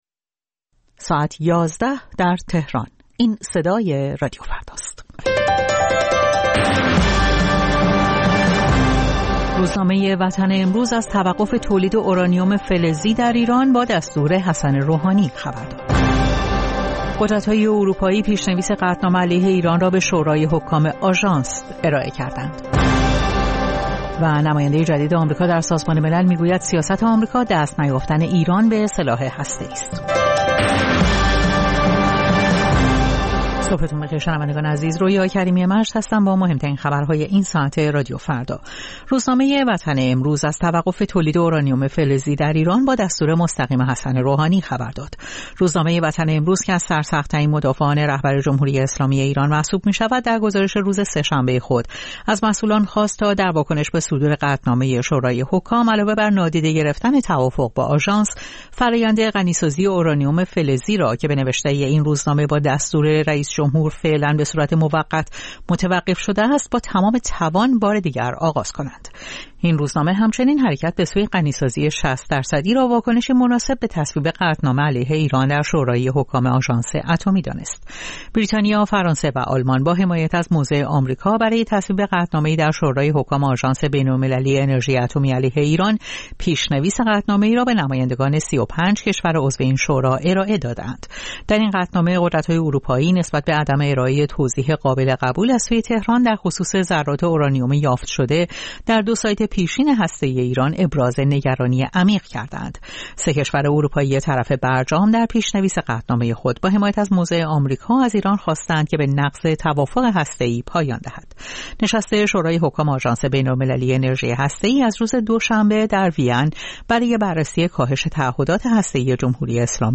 اخبار رادیو فردا، ساعت ۱۱:۰۰